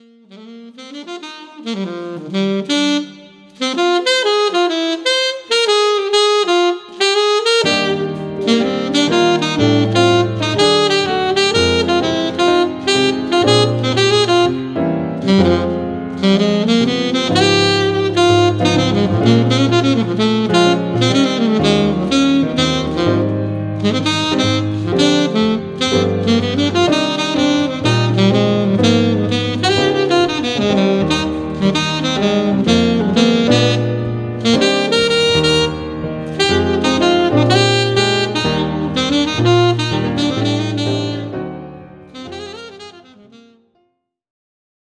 スリリングながらも暖かいJAZZ愛に溢れるDuo作品！
Alto Sax
Piano